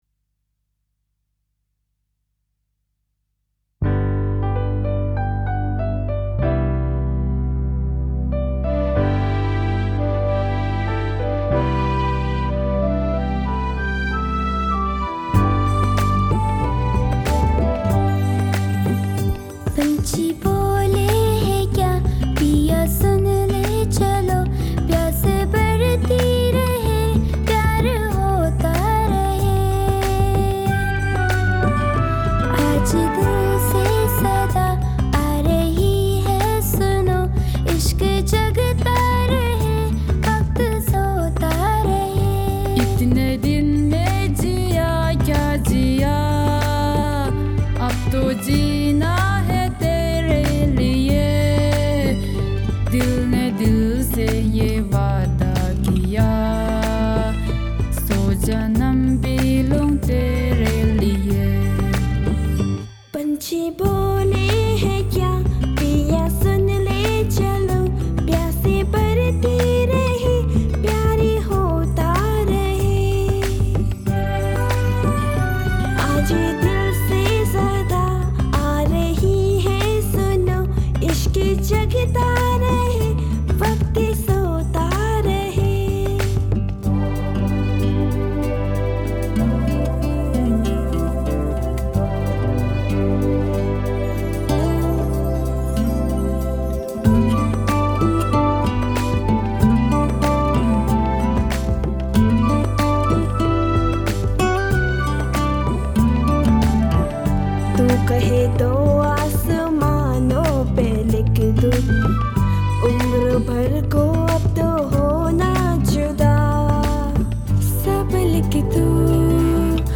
Hindi Bollywood Song